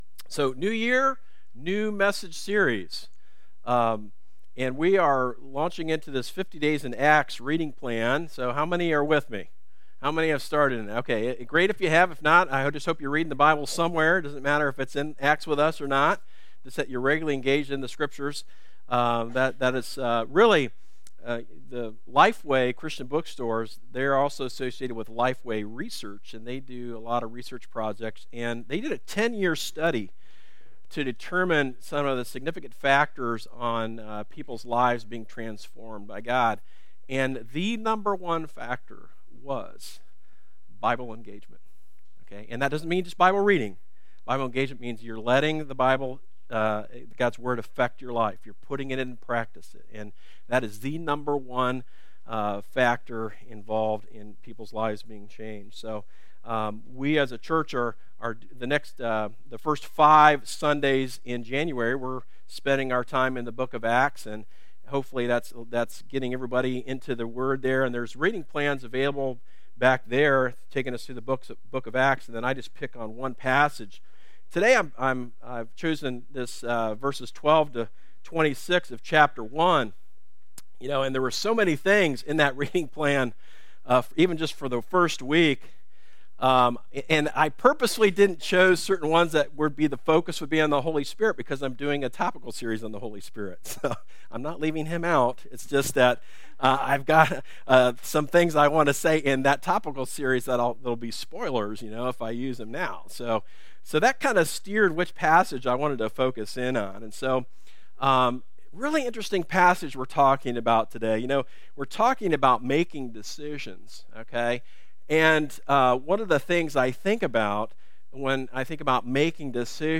A message from the series "Pray Boldly."